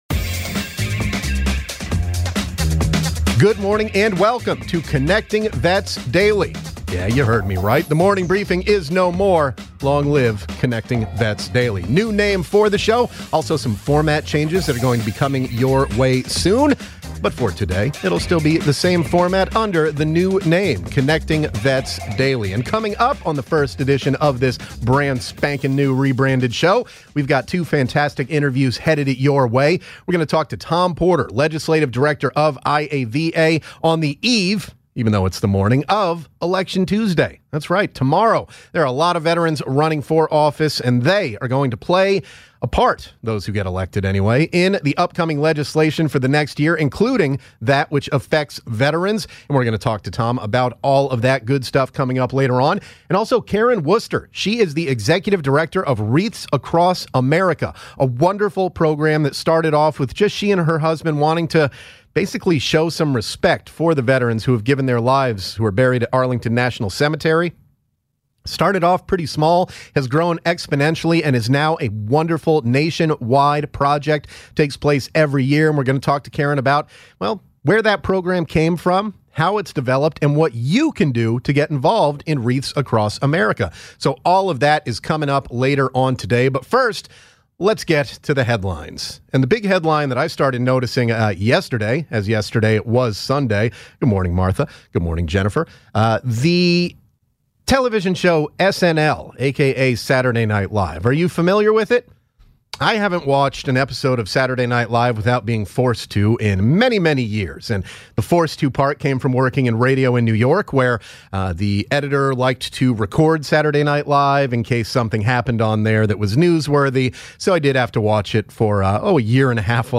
joins the show in studio